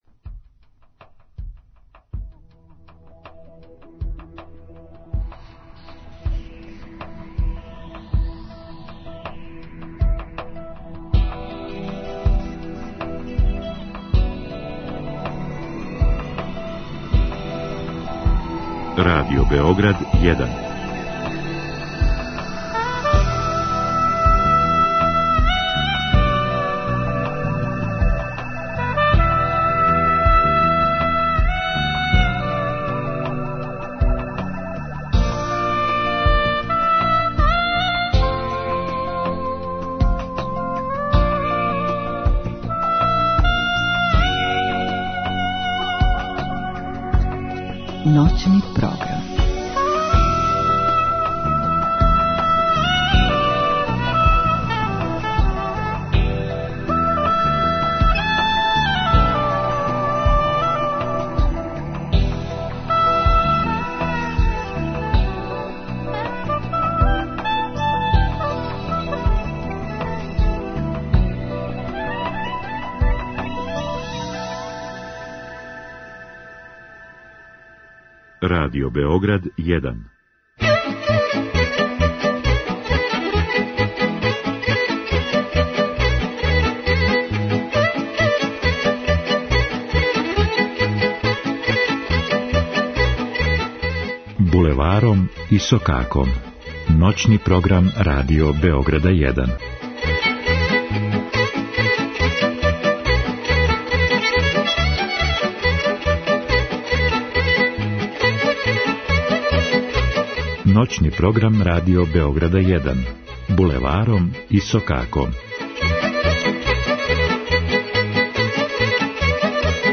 Изворна, староградска и музика у духу традиције.